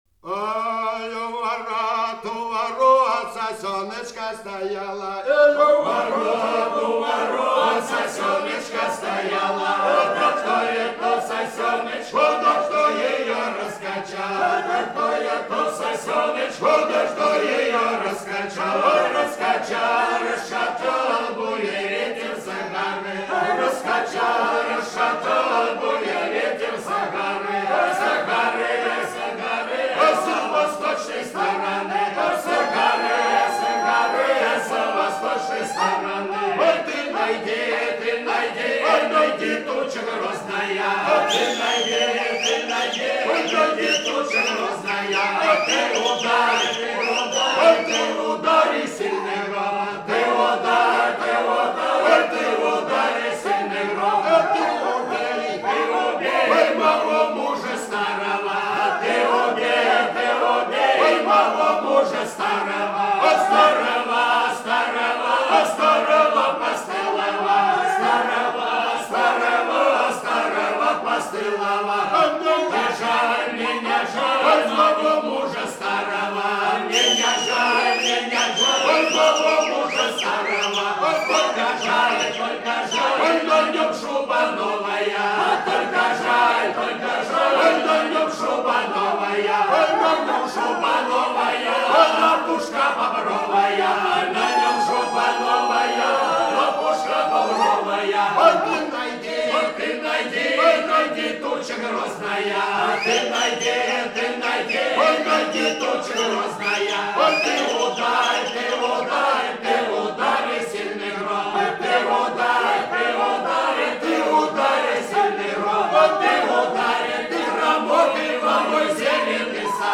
А чуяло мое сердечушко Ай, у ворот, у ворот сосёночка стояла – плясовая. Ансамбль «Бузулук» город Новоаннинский.